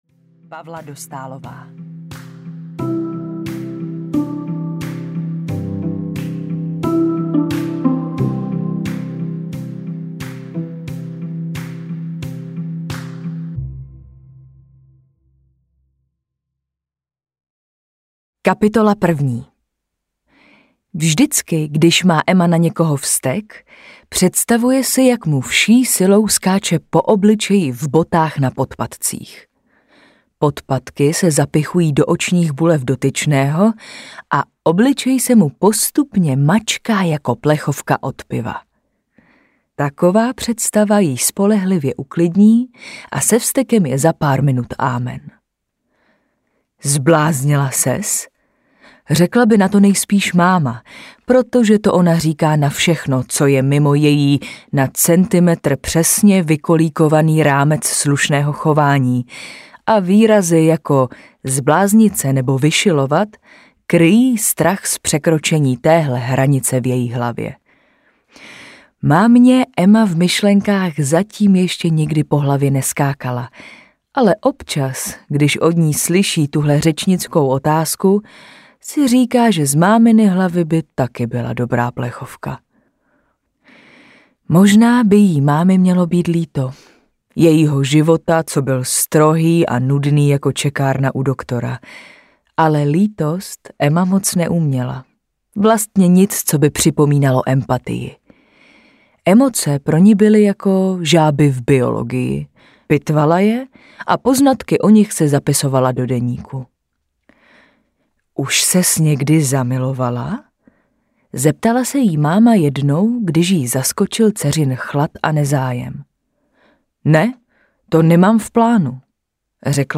Majonéza k snídani audiokniha
Ukázka z knihy